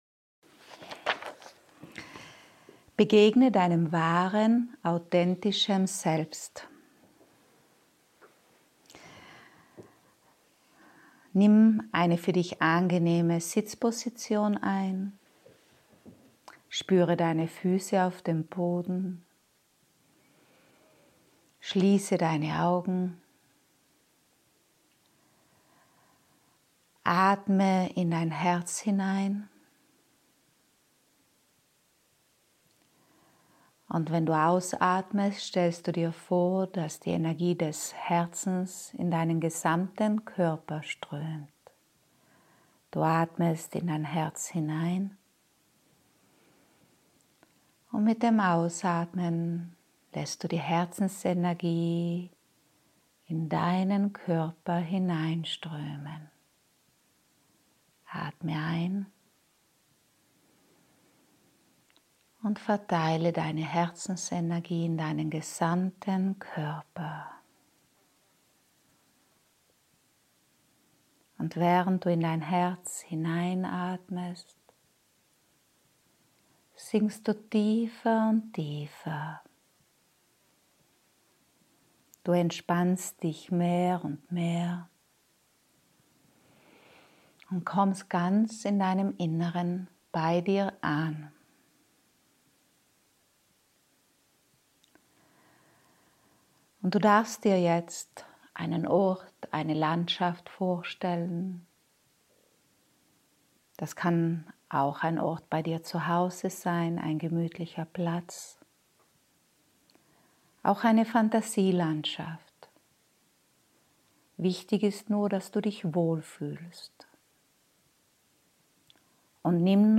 Meditation: Begegnung mit deinem Wahrem Selbst